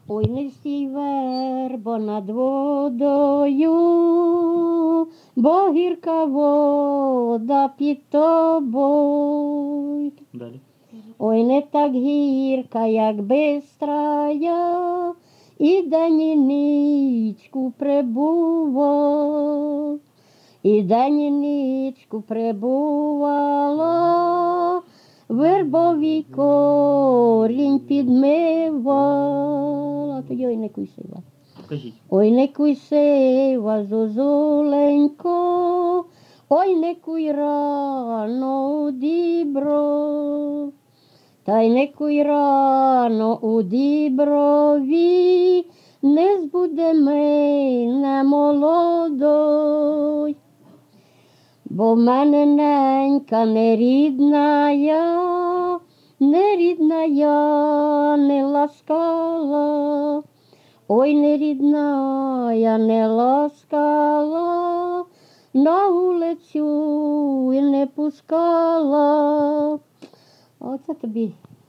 ЖанрКупальські
Місце записус. Будне, Охтирський район, Сумська обл., Україна, Слобожанщина